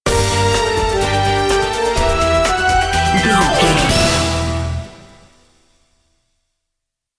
Short music, corporate logo or transition between images,
Fast paced
In-crescendo
Uplifting
Ionian/Major
bright
cheerful/happy
futuristic
industrial
intense
playful
powerful
driving
groovy
funky
synthesiser